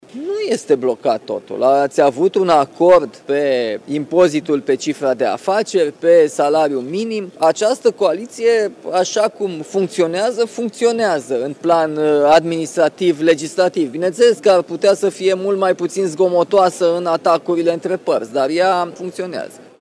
Coaliția de guvernare funcționează în plan administrativ și legislativ, este reacția președintelui Nicușor Dan, întrebat de jurnaliștii aflați la Bruxelles dacă mai există viitor pentru coaliția de guvernare. Șeful statului admite, totuși, că atacurile politice între partide sunt cam zgomotoase.